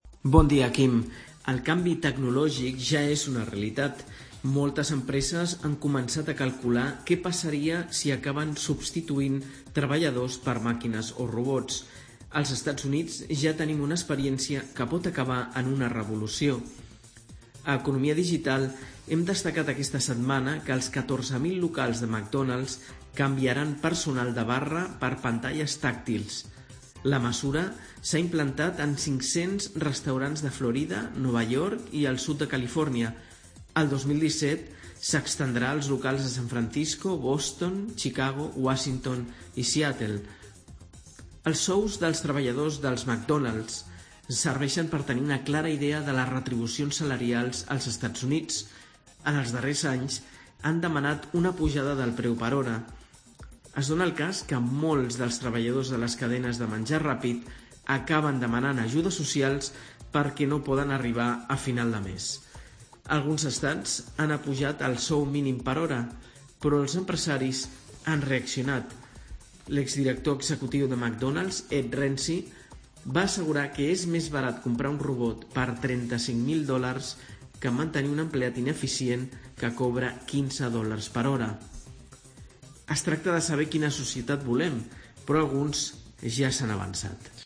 AUDIO: McDonald's comença a substituir els venedors d'hamburgueses per robots. Entrevista